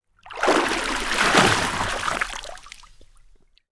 Water_55.wav